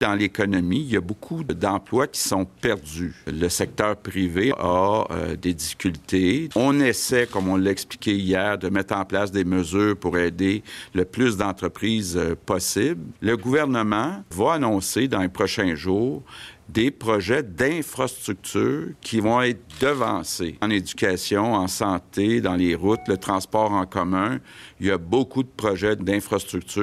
En point de presse vendredi, il évoque plutôt un retour en classe vers le début mai pour les niveaux primaire et secondaire, alors que les étudiants de niveaux collégial et universitaire devraient pouvoir terminer leur session en télé-enseignement.